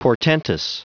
Prononciation du mot : portentous
portentous.wav